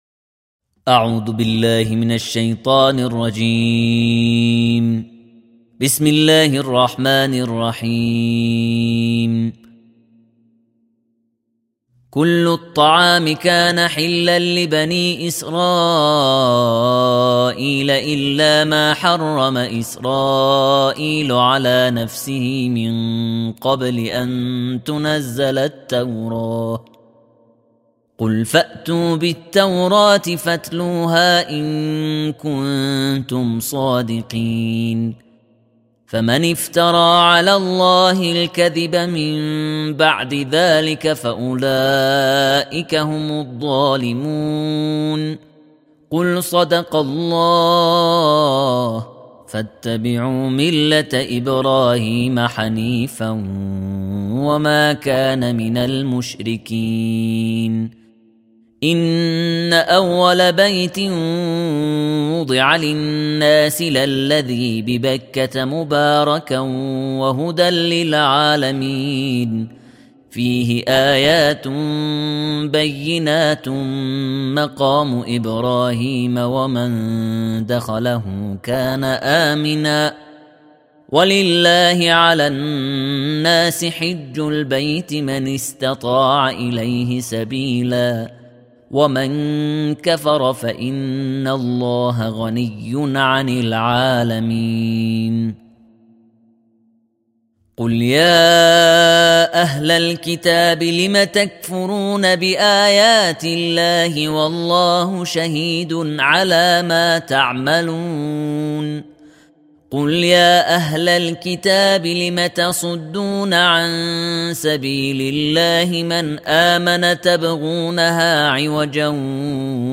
تلاوت جزء چهارم قرآن
ترتیل جزء 4 قرآن